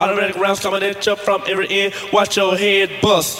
Automatic Rounds.wav